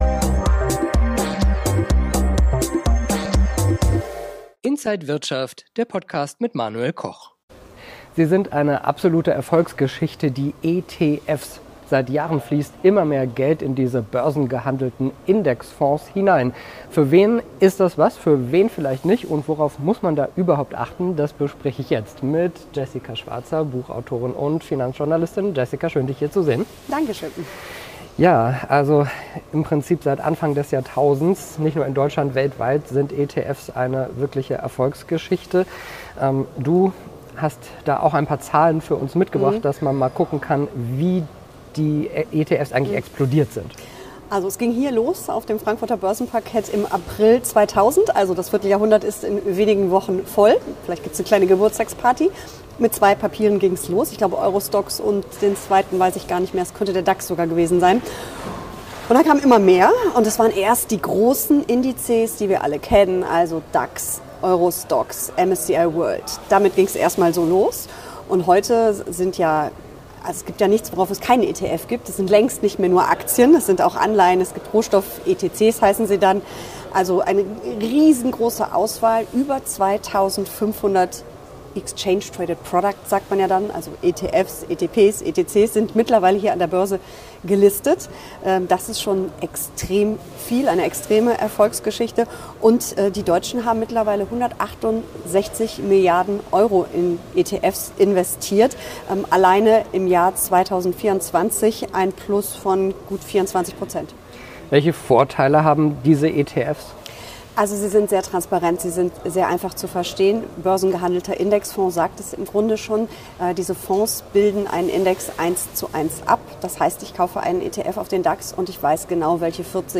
Buchautorin und Finanzjournalistin im Interview von Inside
an der Frankfurter Börse